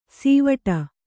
♪ sīvaṭa